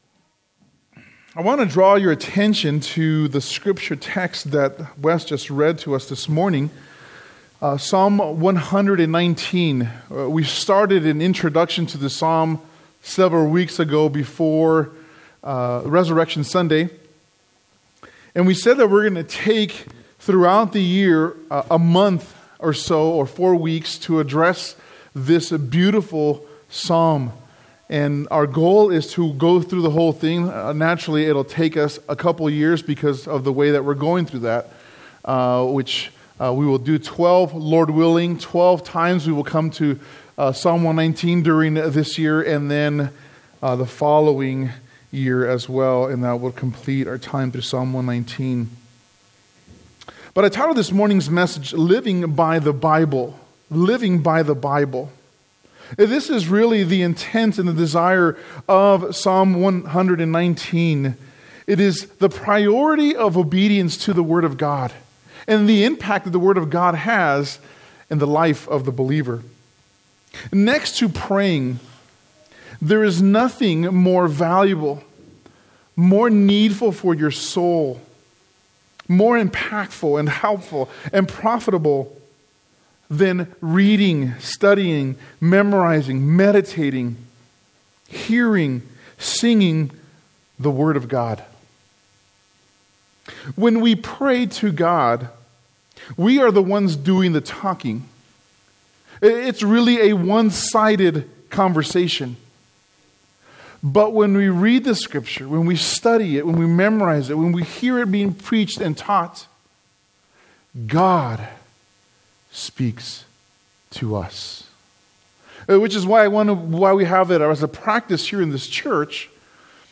teaches from Psalm 119:1-8.